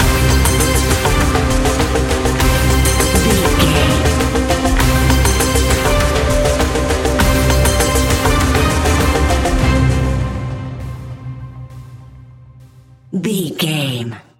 Ionian/Major
C♭
electronic
techno
trance
synthesizer
synthwave